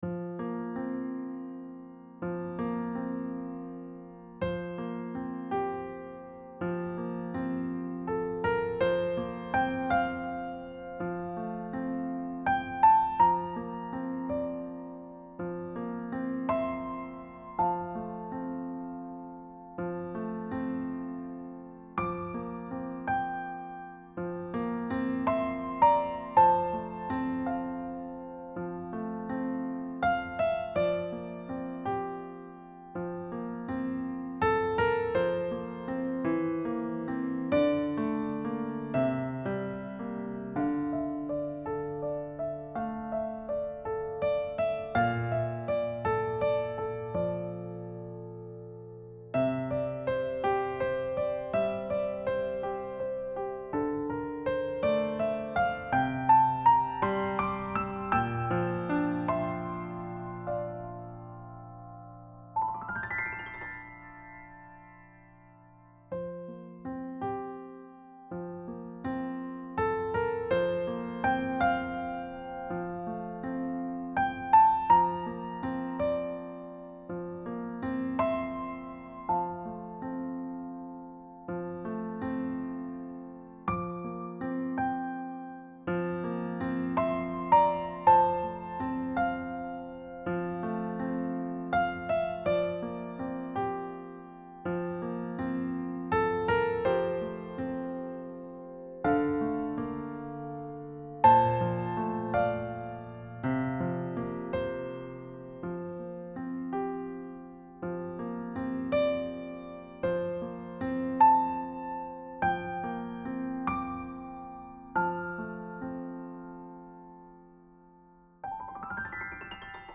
a mysterious and lyrical second movement
Key: F Major
Time signature: 6/8
• Expressive legato phrasing in the right hand
• Thoughtful pedal use for sustain and color
Subtle rubato and gentle tempo for character
Soft glissandos for effect without overpowering the line